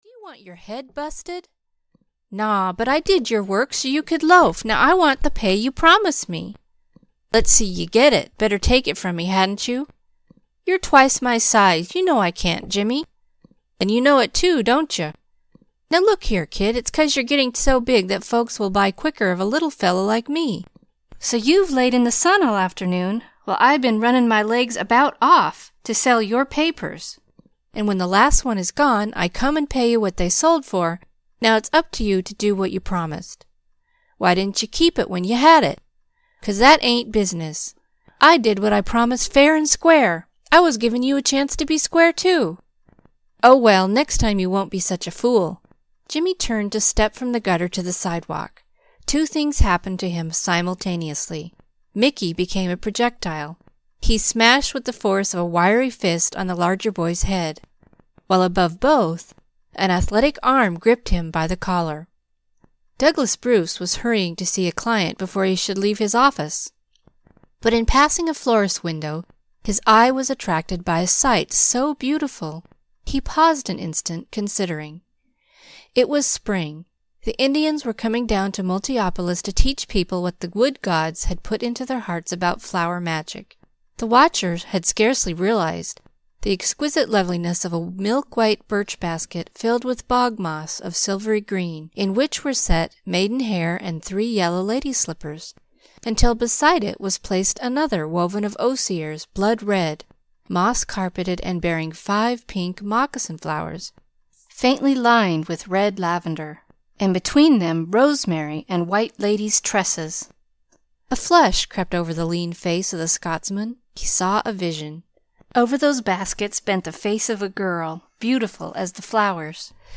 Michael O'Halloran (EN) audiokniha
Ukázka z knihy